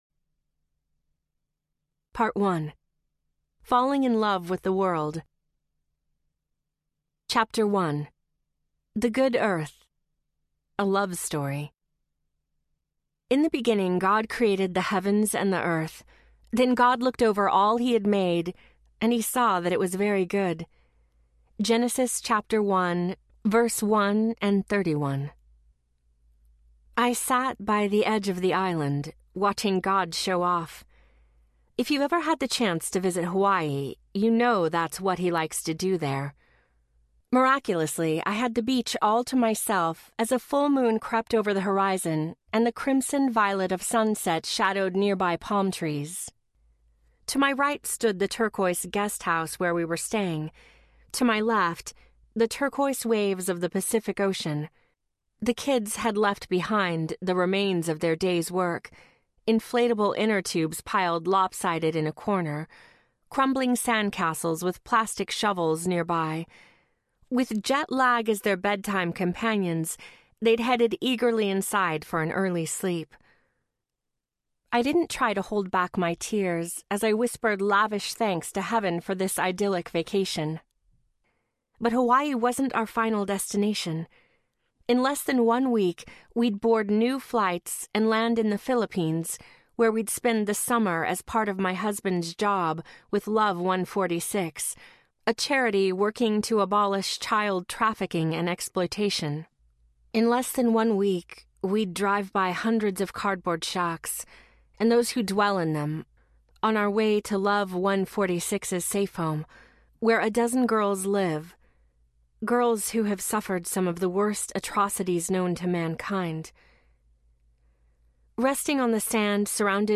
Give Your Child the World Audiobook
Narrator
6.9 Hrs. – Unabridged